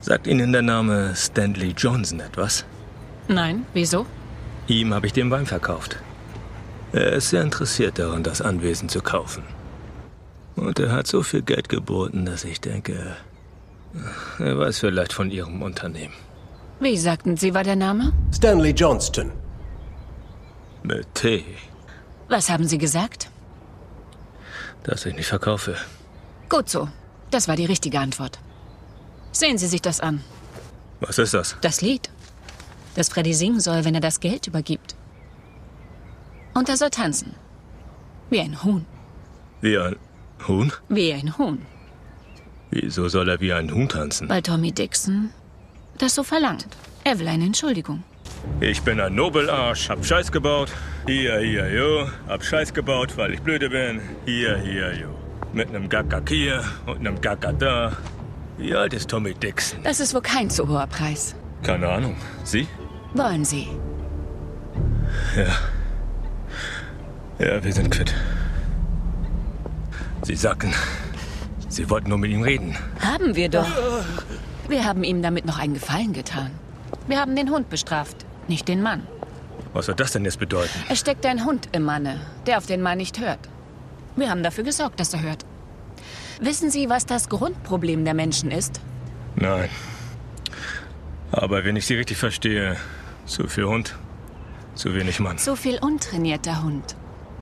Deutsch Werbung Lenor